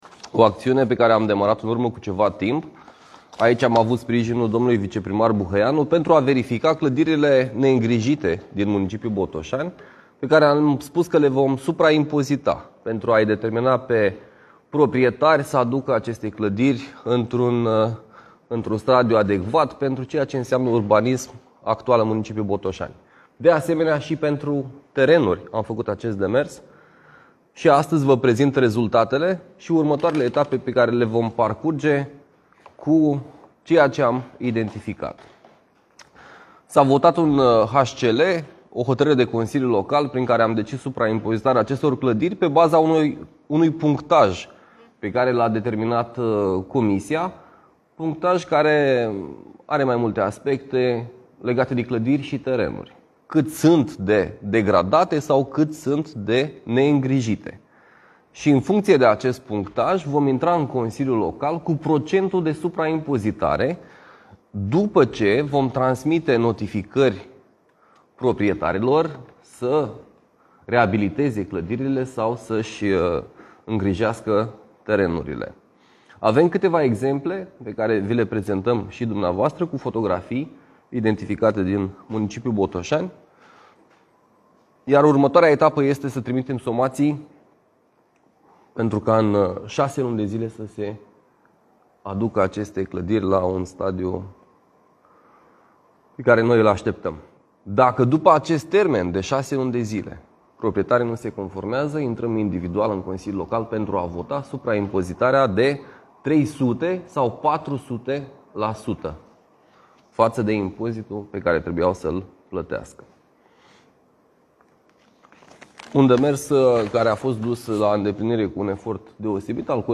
Primarul Cosmin Andrei și viceprimarul Bogdan Buhăianu explică: